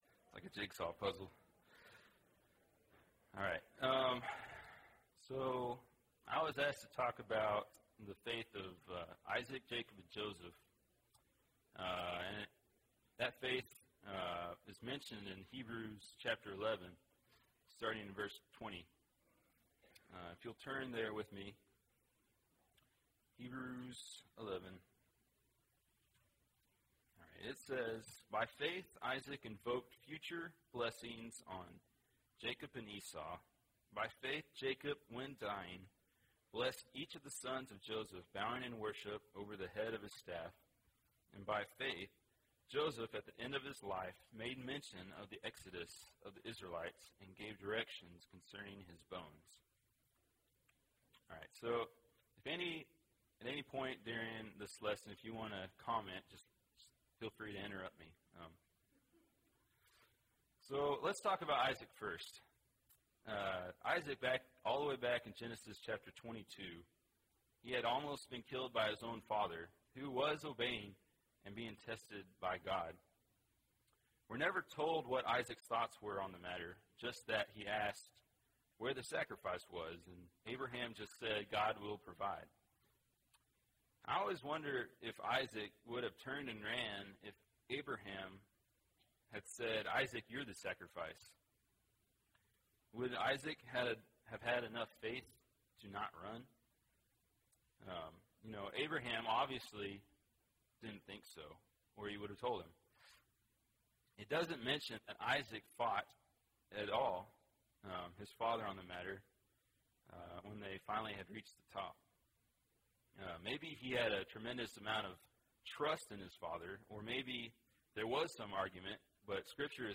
By Faith: Isaac, Jacob and Joseph (5 of 10) – Bible Lesson Recording